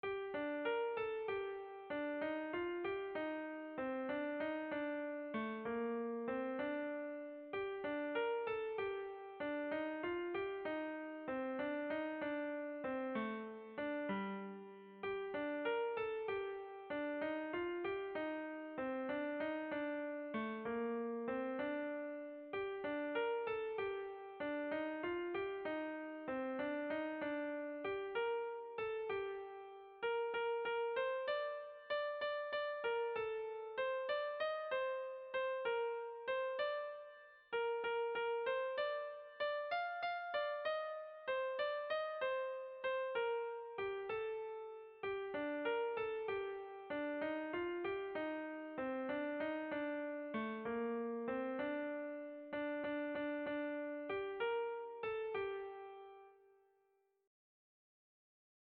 Kontakizunezkoa
AAA2B1B2A